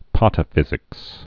(pätə-fĭzĭks)